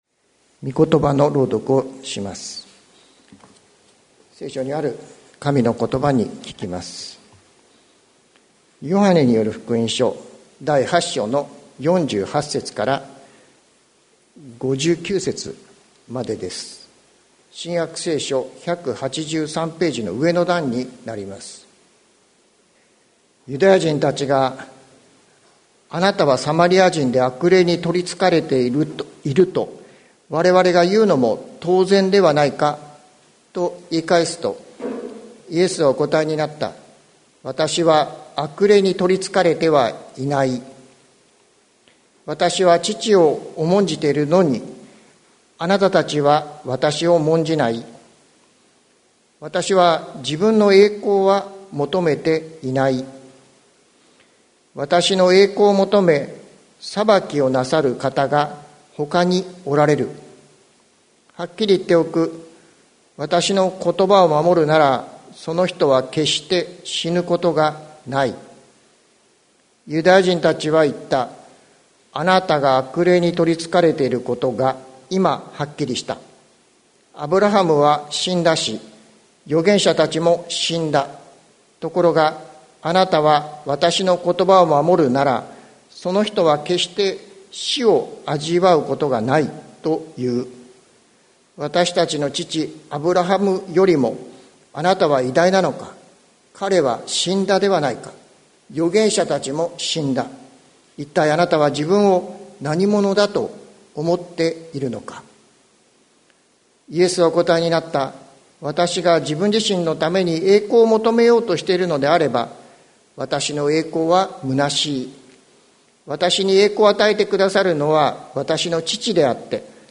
2022年08月28日朝の礼拝「ひとり子の犠牲」関キリスト教会
説教アーカイブ。